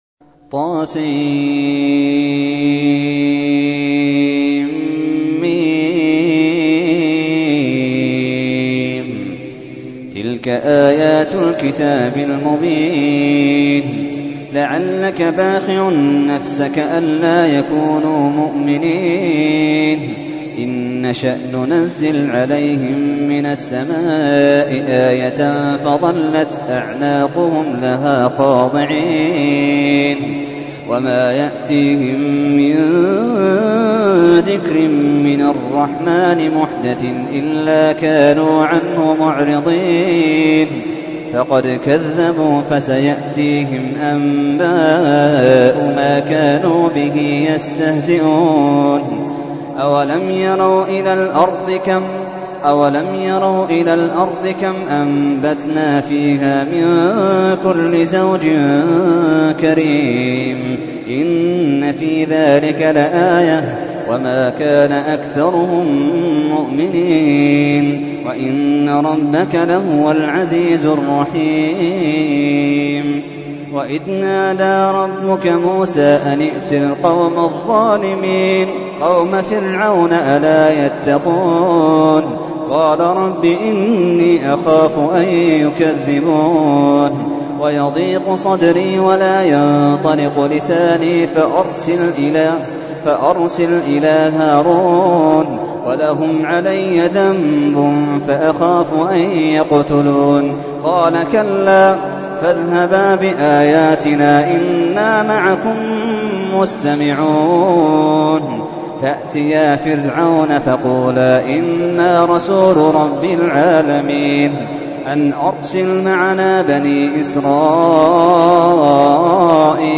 تحميل : 26. سورة الشعراء / القارئ محمد اللحيدان / القرآن الكريم / موقع يا حسين